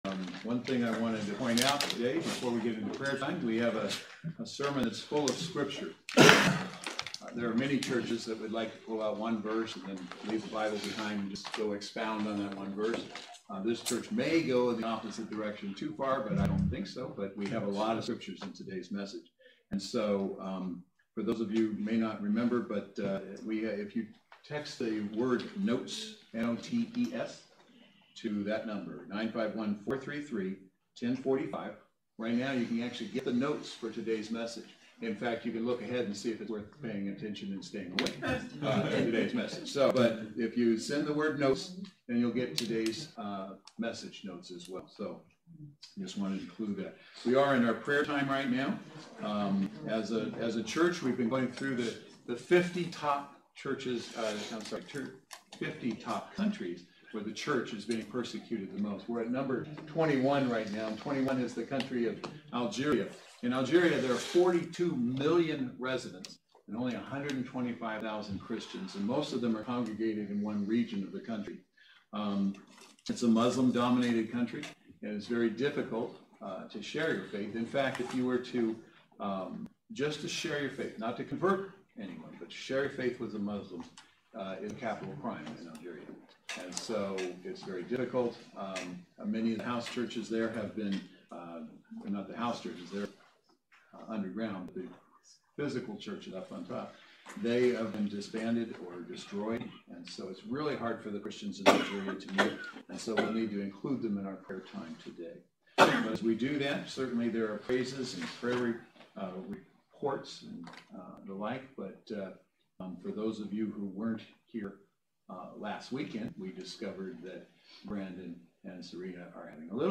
Series: Footsteps of Paul: Footprints of Grace Service Type: Saturday Worship Service